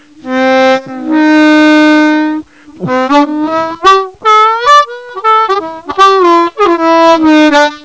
The Dreaded One Hole Overblow.
overblow1.wav